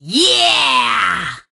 Penny Portrait.png潘妮是海盗船上的一员，声音像个假小子，其实超级可爱(●'◡'●)
Penny_start_vo_02.ogg